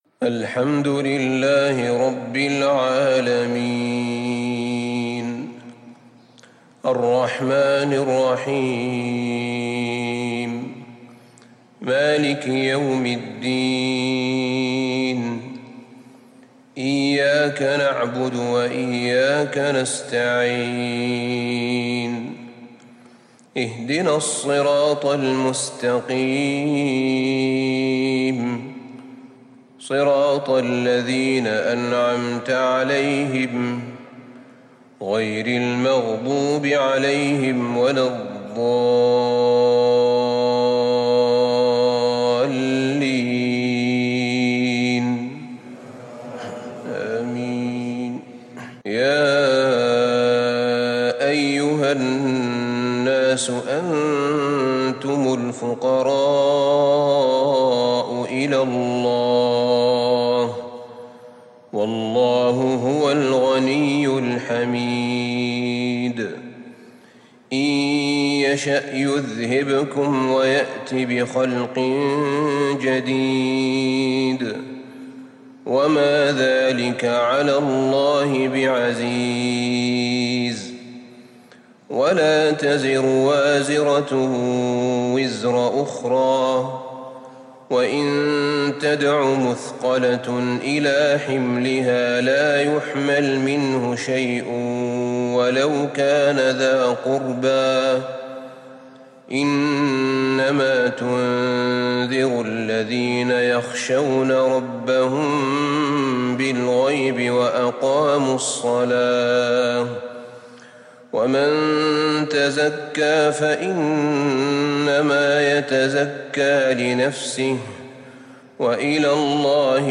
صلاة الفجر للشيخ أحمد بن طالب حميد 14 جمادي الأول 1442 هـ
تِلَاوَات الْحَرَمَيْن .